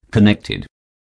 connection.connected.wav